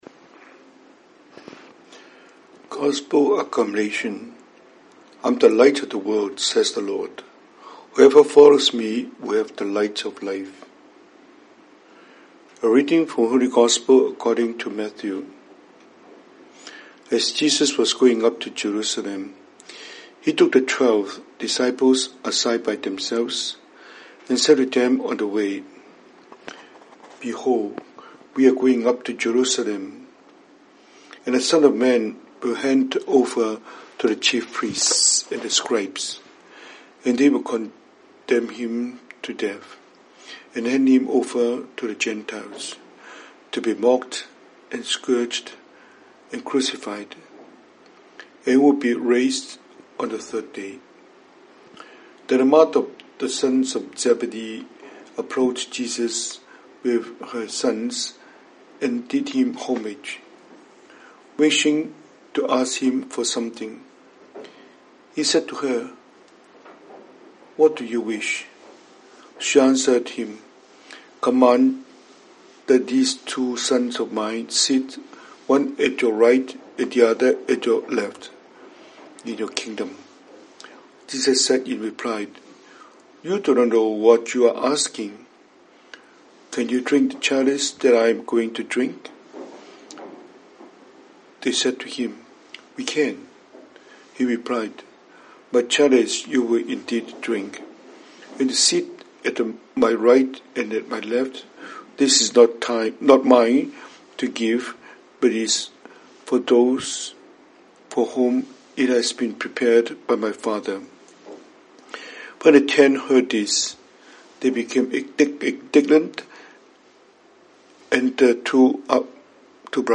中文講道,